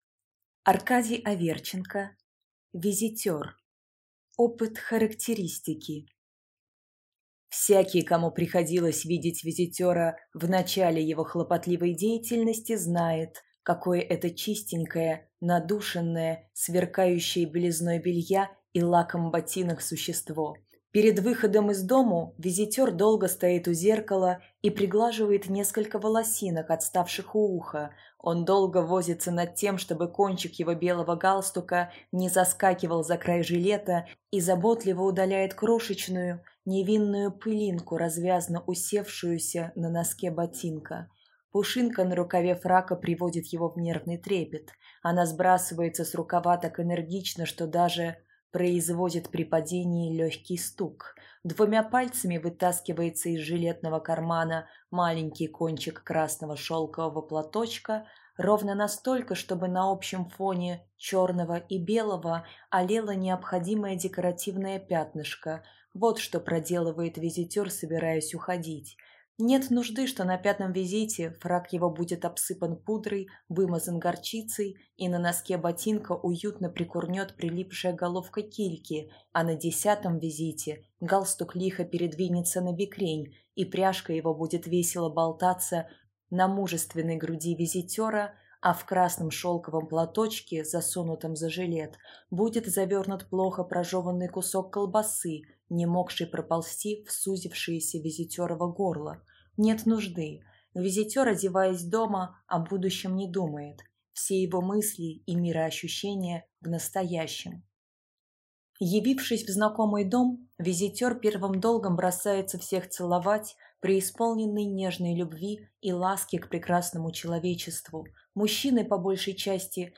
Аудиокнига Визитер | Библиотека аудиокниг